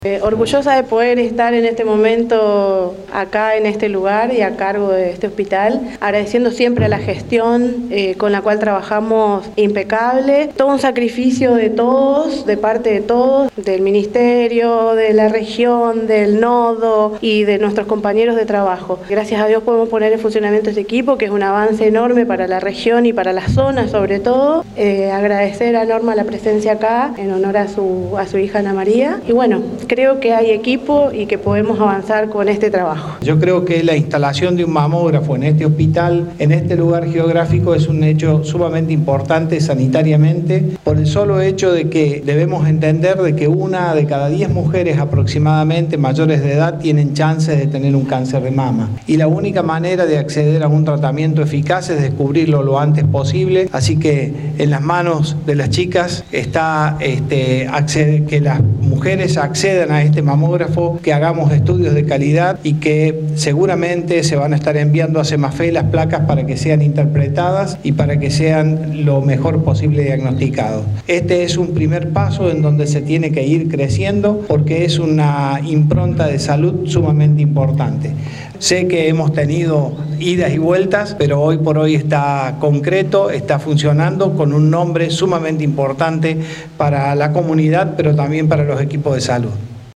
Ernesto Bosco, Sec. de Gestión Territorial.